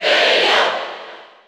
Crowd cheers (SSBU) You cannot overwrite this file.
Bayonetta_Cheer_French_PAL_SSBU.ogg.mp3